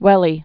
(wĕlē)